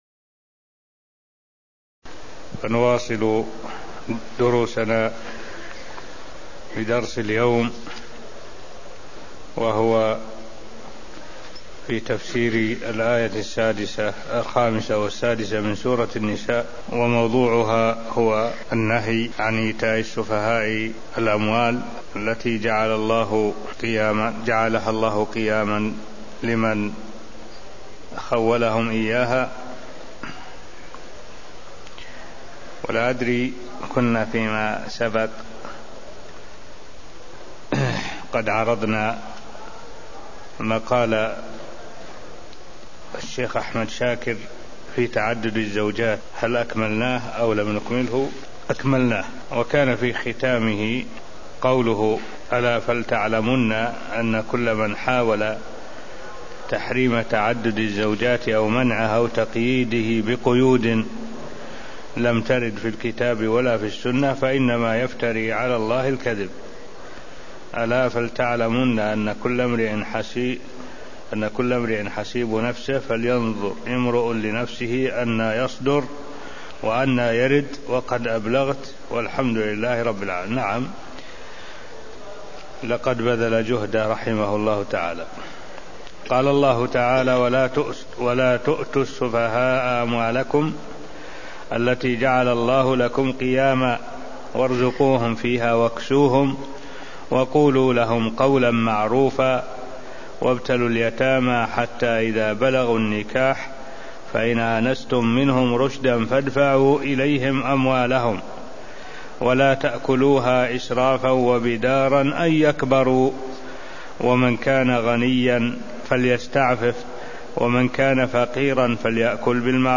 المكان: المسجد النبوي الشيخ: معالي الشيخ الدكتور صالح بن عبد الله العبود معالي الشيخ الدكتور صالح بن عبد الله العبود سورة النساء 5-6 (0207)